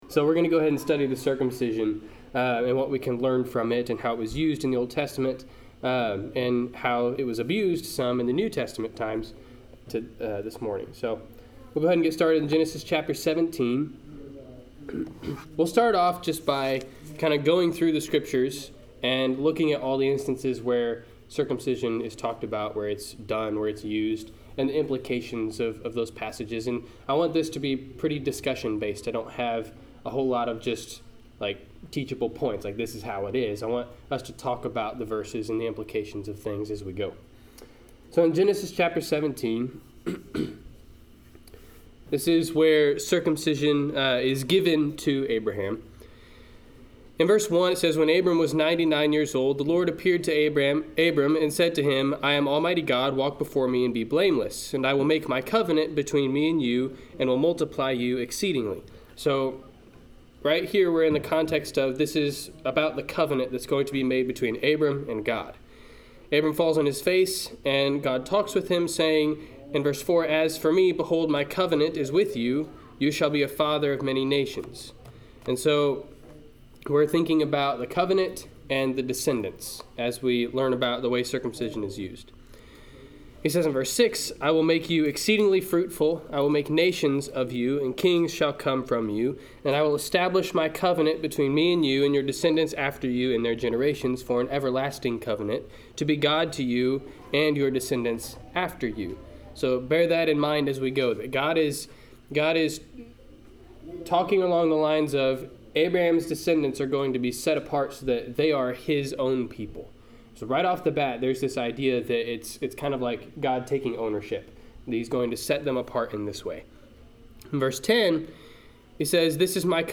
Service Type: Sunday 10:00 AM